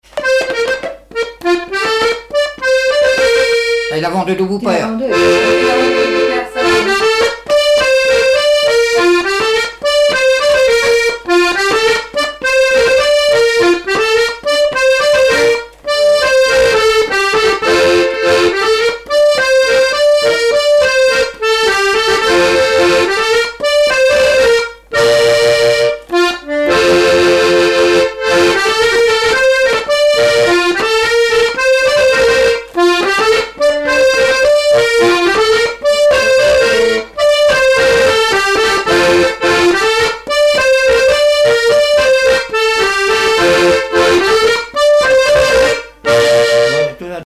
Résumé instrumental
danse : branle : avant-deux
Répertoire du musicien sur accordéon chromatique
Pièce musicale inédite